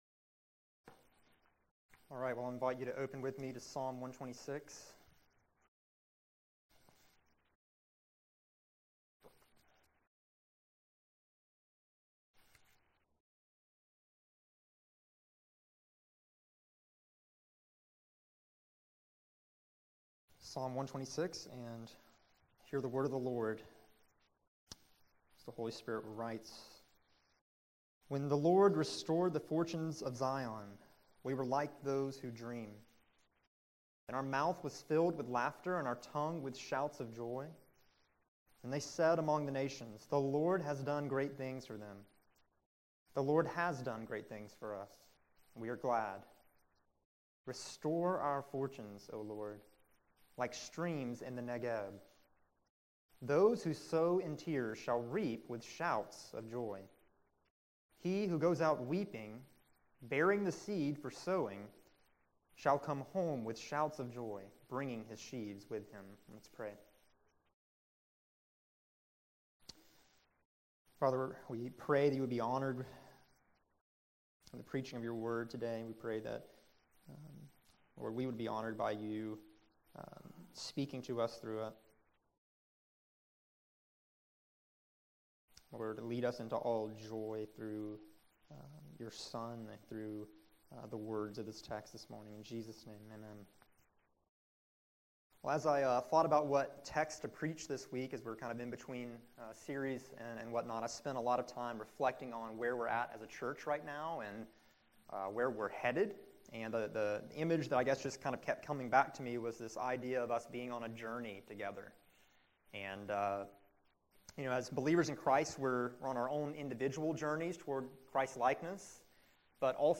Today’s sermon comes as an encouragement to the body of Vine Street while we go through great trails, changes, grief and joys.